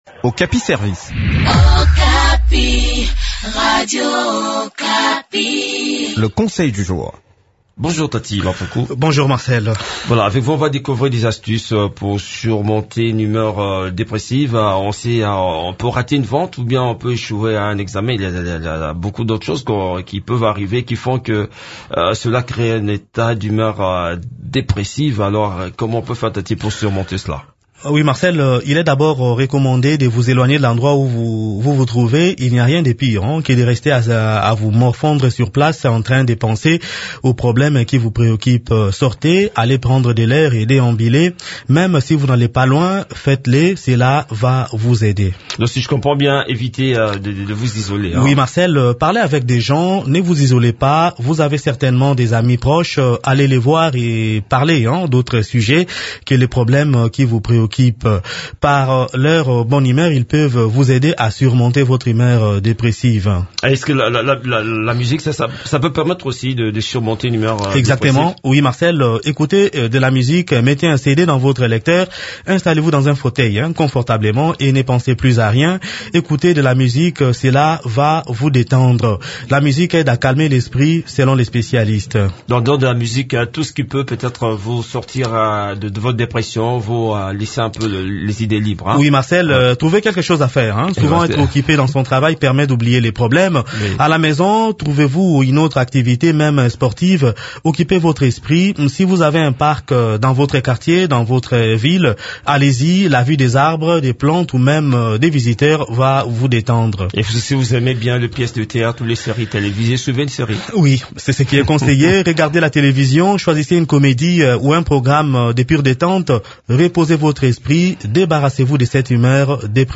Découvrez des astuces qui peuvent vous aider à surmonter une humeur dépressive dans cette chronique